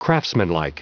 Prononciation du mot craftsmanlike en anglais (fichier audio)
Prononciation du mot : craftsmanlike